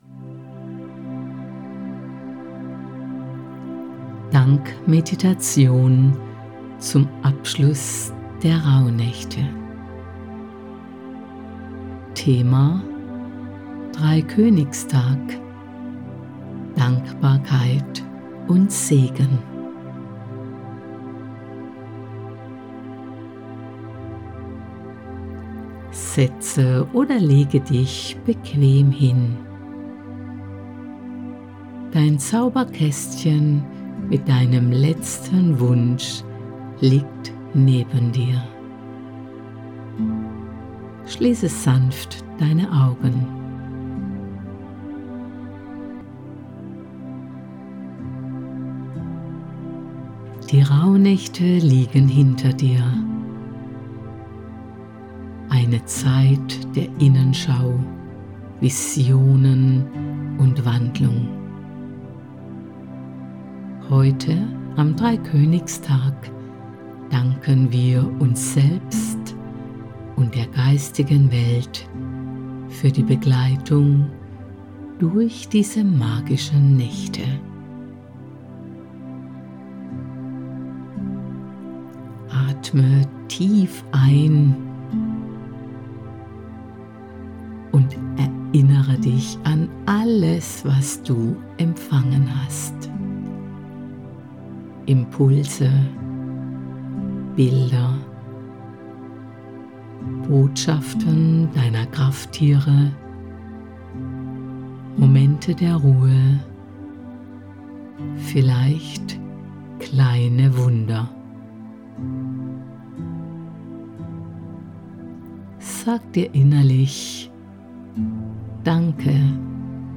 Die Magie der Rauhnächte – 13 geführte Meditationen für die Zeit zwischen den Jahren
Sanfte Musik untermalt die Reise durch die Rauhnächte mit ihren verschiedenen Themen – um Altes loszulassen, Neues willkommen zu heißen und der Weisheit deiner Seele zu lauschen.